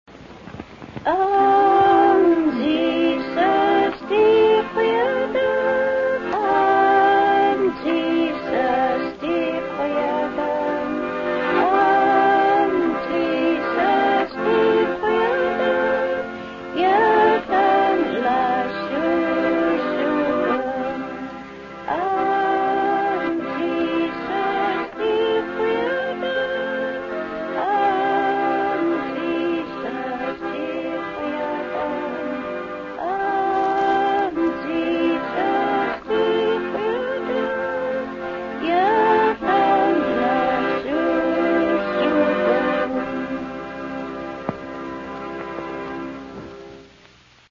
Here are six short gospel songs sung in Haida.
gospel-song-03.mp3